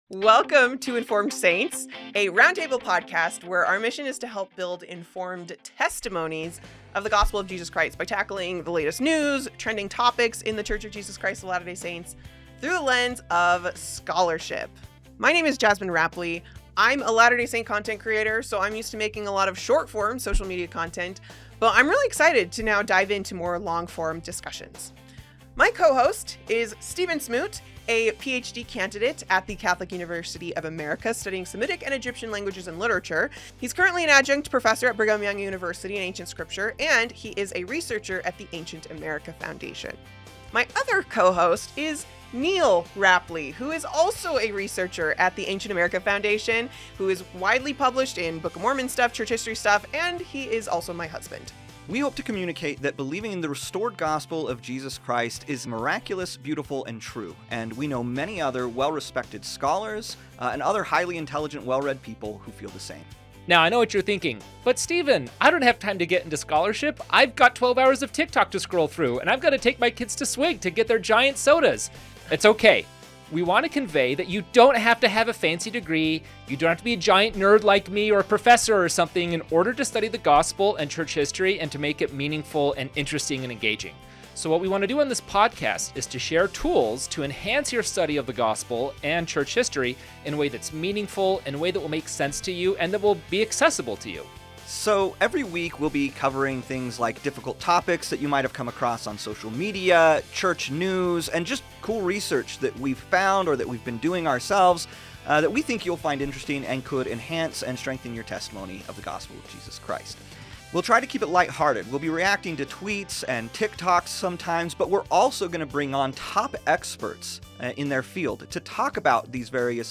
Welcome to Informed Saints, a roundtable podcast dedicated to strengthening testimonies of Jesus Christ through thoughtful study and open conversation.